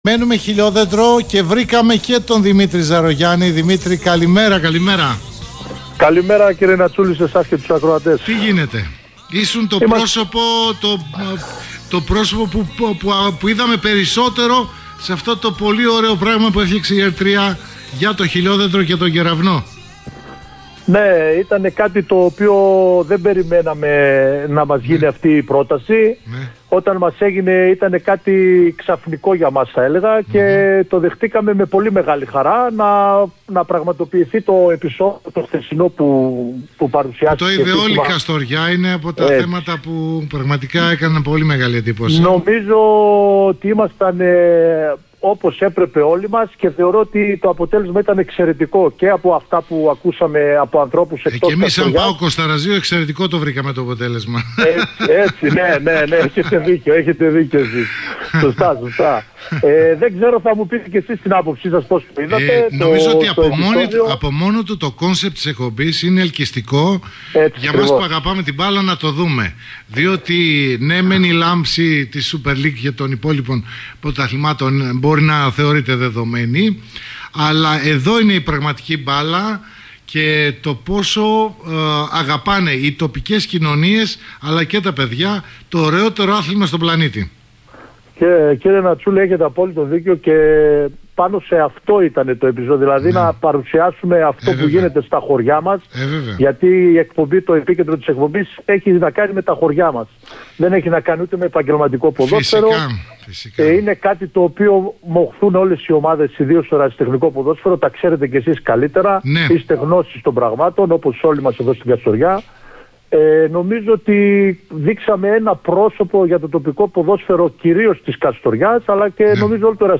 (συνέντευξη)